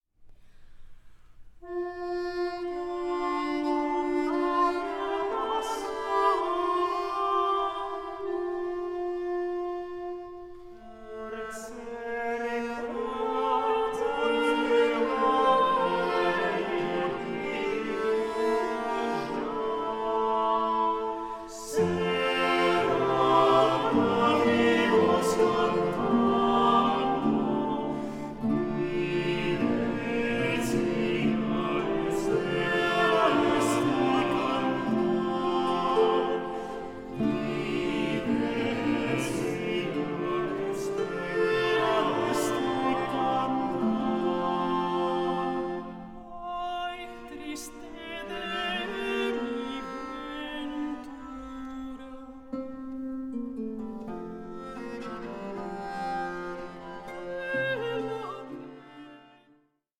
MELANCHOLIC SOLACE FOR TROUBLED TIMES